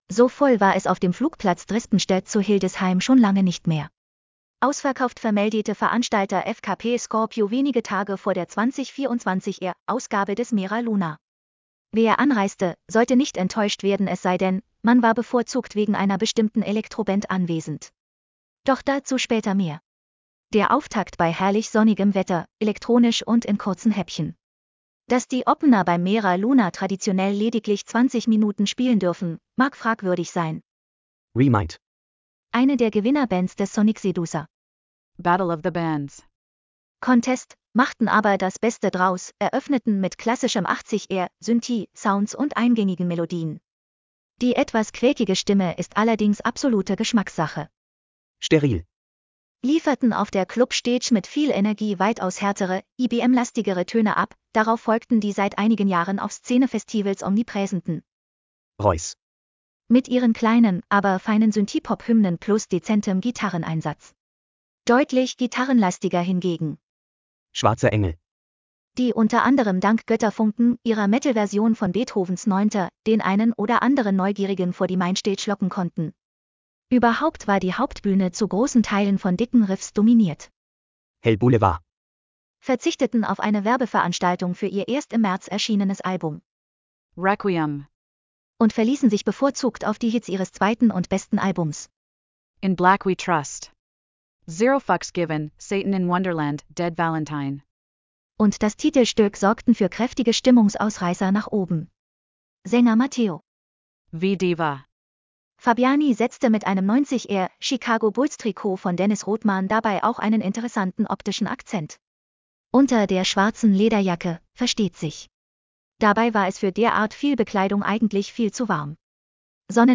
Lass Dir den Beitrag vorlesen: /wp-content/TTS/179171.mp3 Der Auftakt bei herrlich sonnigem Wetter: elektronisch und in kurzen Häppchen.